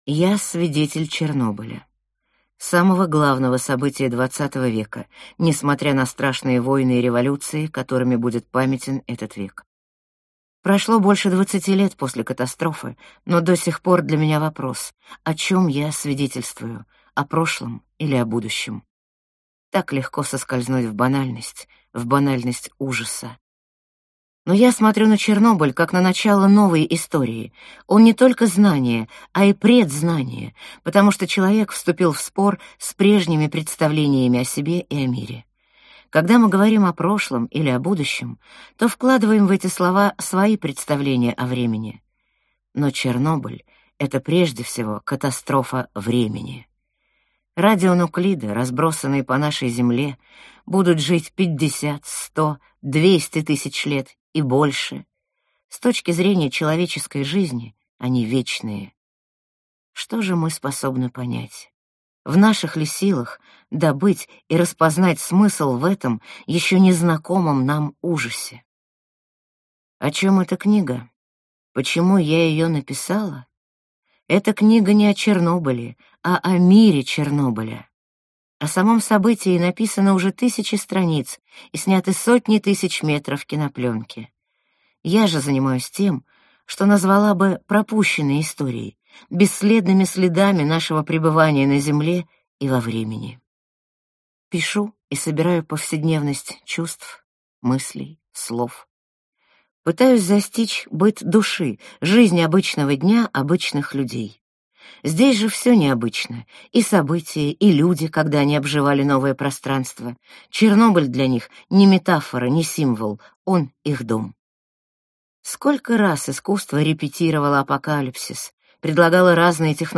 Аудиокнига Чернобыльская молитва. Хроника будущего | Библиотека аудиокниг